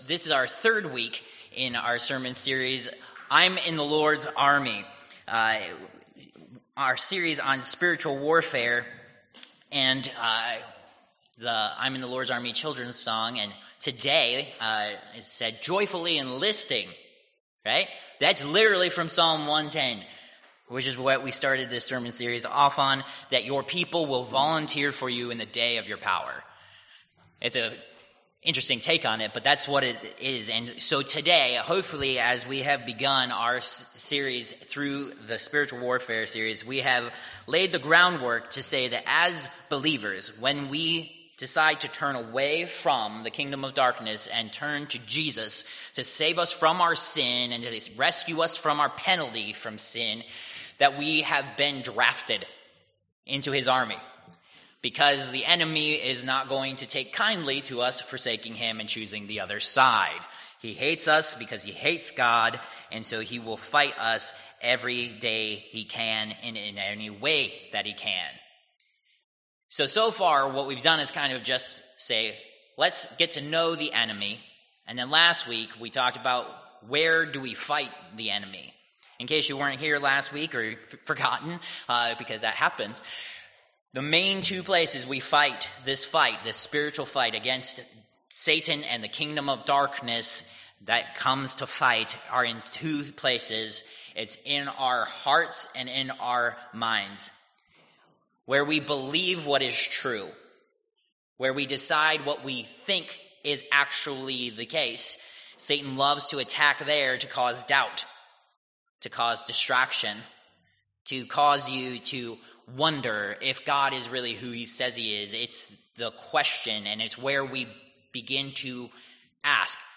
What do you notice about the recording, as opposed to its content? I'm in the Lord's Army Passage: Eph. 6:10-18 Service Type: Worship Service « Protected